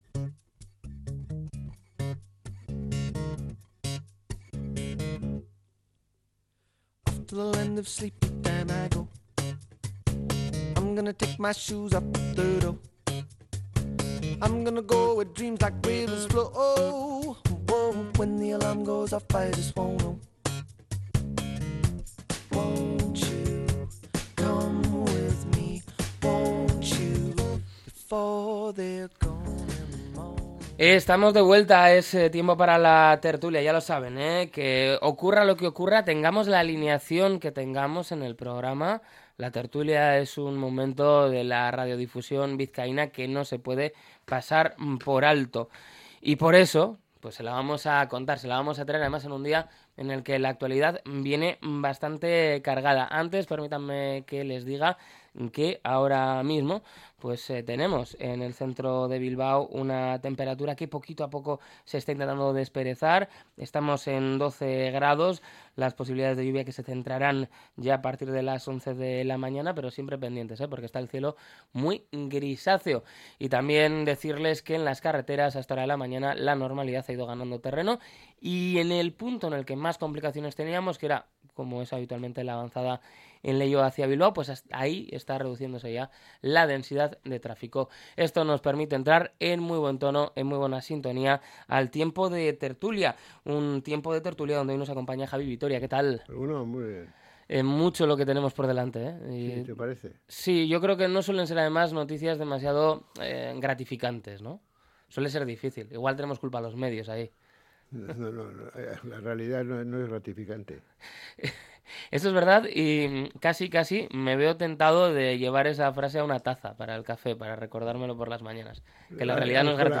La tertulia 18-03-25.